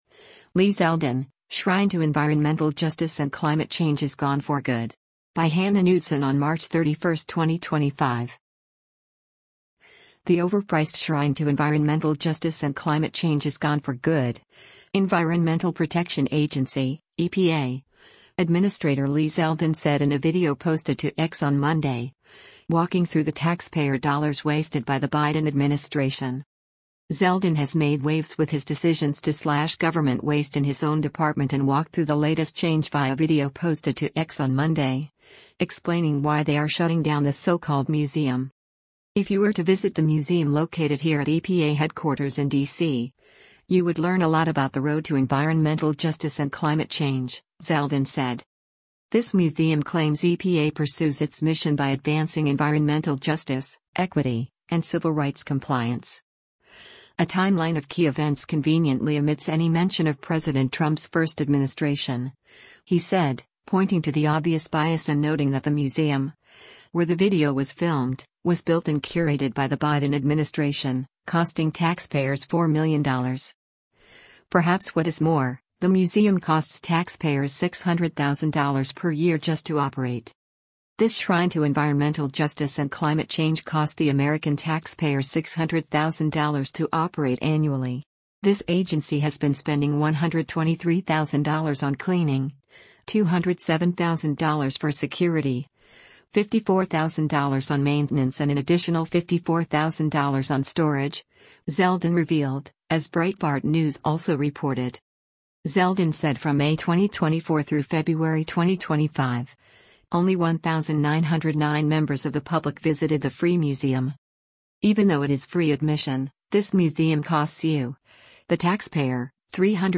The overpriced “shrine to environmental justice and climate change” is gone for good, Environmental Protection Agency (EPA) Administrator Lee Zeldin said in a video posted to X on Monday, walking through the taxpayer dollars wasted by the Biden administration.
“This museum claims EPA pursues its mission by advancing environmental justice, equity, and civil rights compliance. A timeline of key events conveniently omits any mention of President Trump’s first administration,” he said, pointing to the obvious bias and noting that the museum, where the video was filmed, was “built and curated by the Biden administration,” costing taxpayers $4 million.